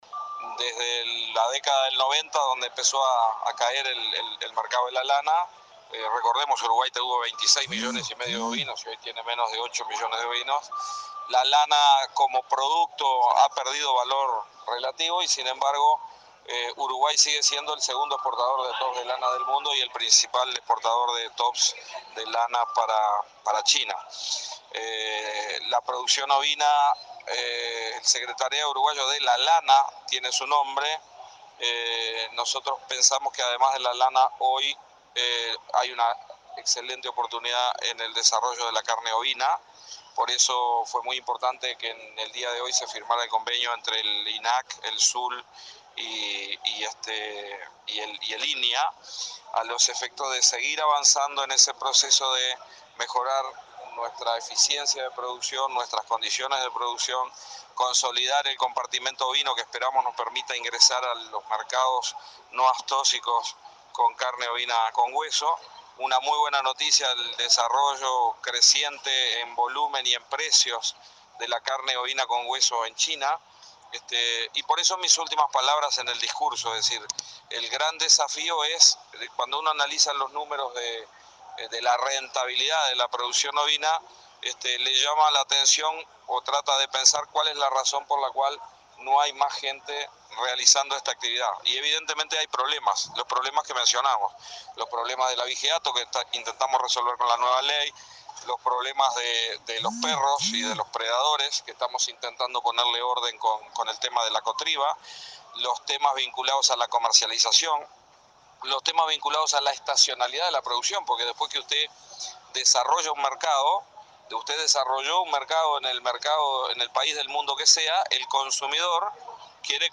Las oportunidades para el sector ovino nacional pasan por robustecer la producción cárnica y el desarrollo de lanas finas para colocarlas en el exterior, reflexionó el titular de Ganadería, Tabaré Aguerre, en el lanzamiento de la zafra 2017, donde se firmó un convenio interinstitucional para innovar la producción.